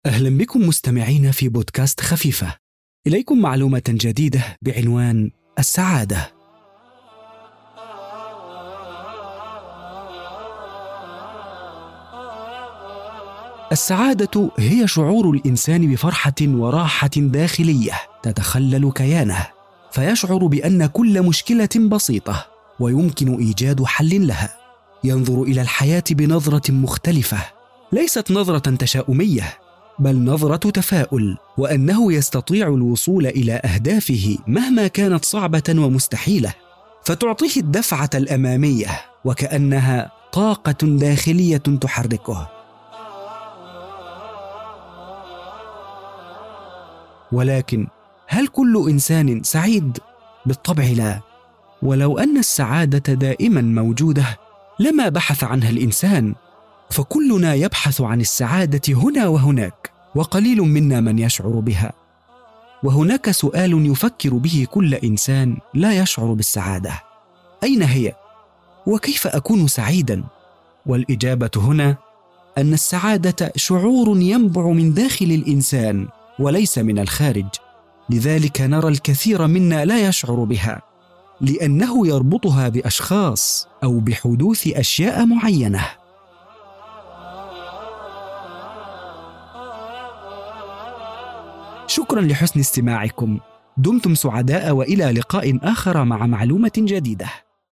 السعادة – نص أدبي
• ذكر
• مذيع أو متحدث رسمي
• العربية الفصحى
• باريتون Baritone (متوسط العرض)
• في منتصف العمر ٣٥-٥٥